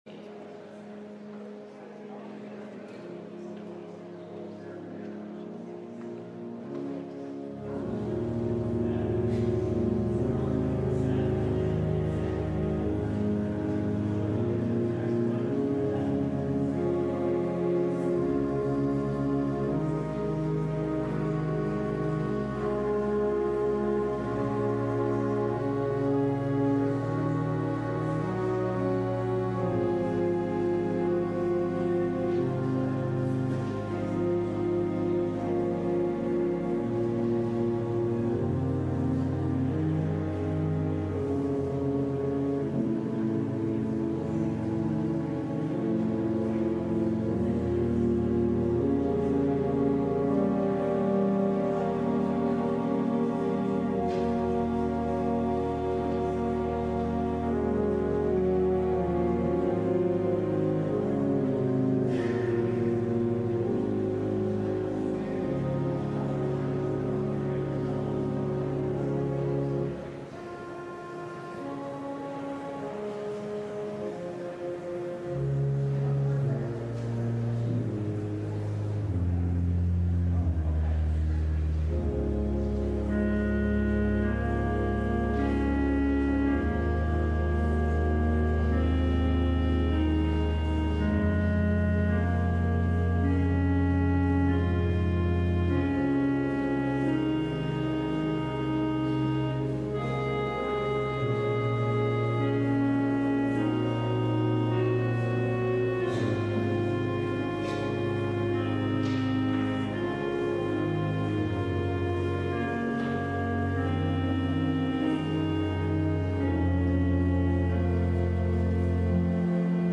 LIVE Morning Worship Service - Kindness
Congregational singing—of both traditional hymns and newer ones—is typically supported by our pipe organ.